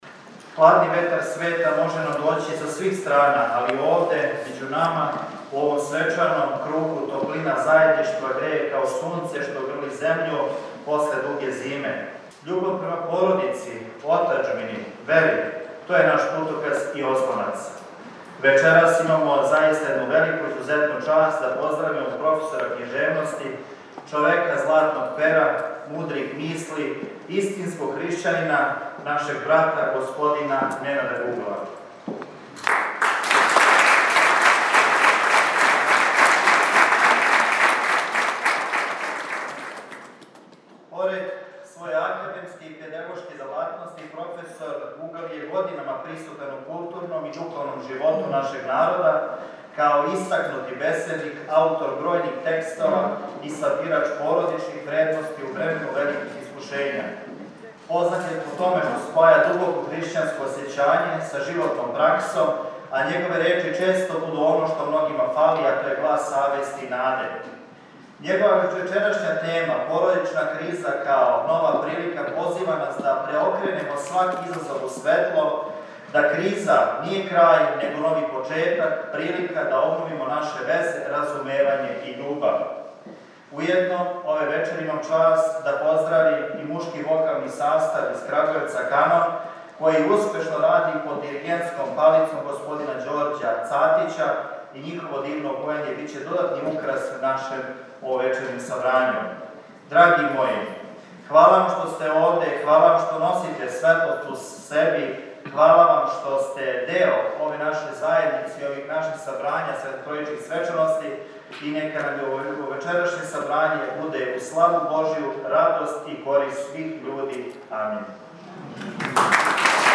У оквиру Светотројичких духовних свечаности, поводом славе Старе Милошеве цркве, епархија Шумадијска, под покровитељством града Крагујевца, сваке године током читавог месеца јуна организује бројна предавања и богат уметнички програм.